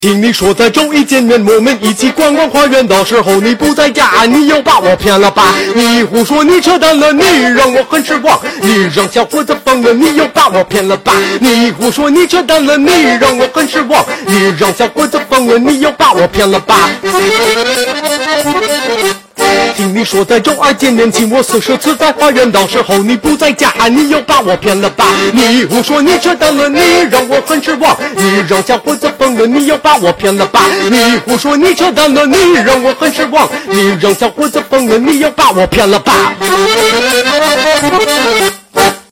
Ukrainian Folk Song. Performed in Chinese on accordion.